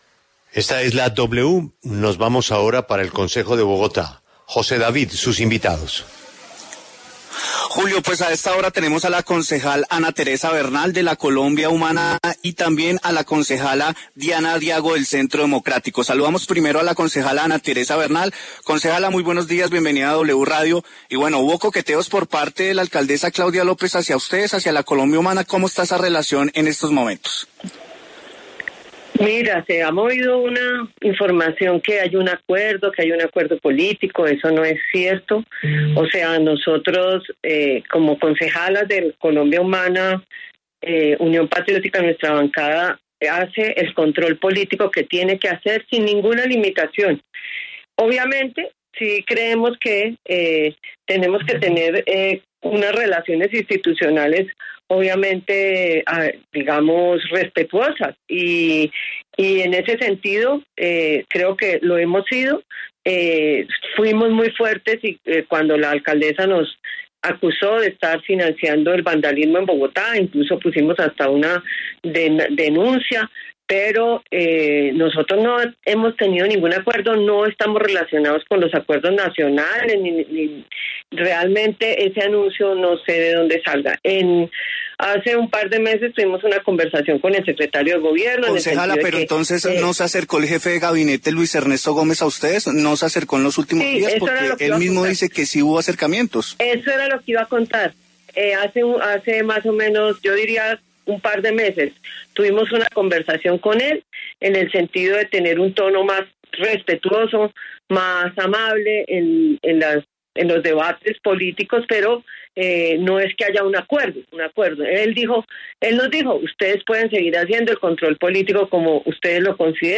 Las concejales Ana Teresa Bernal y Diana Diago hablaron en La W sobre esta polémica.